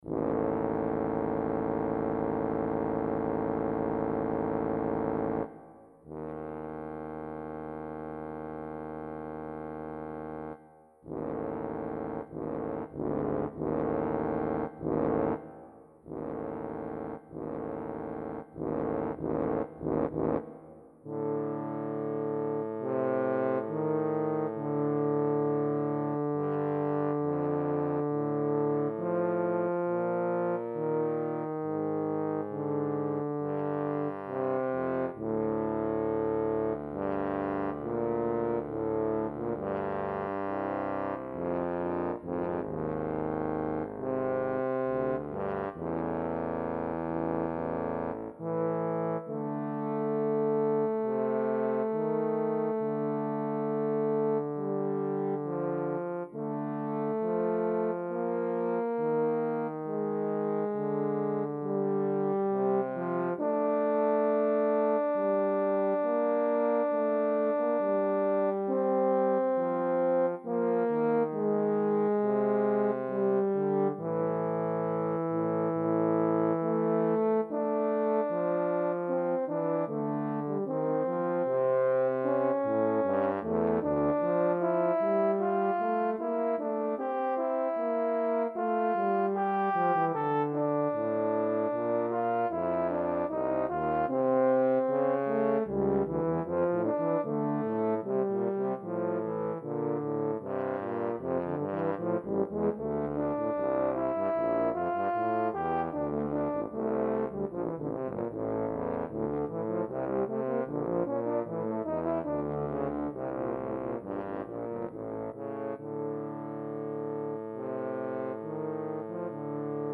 For 2 Bass Trombones.
Adagio Misterioso.
Allegro.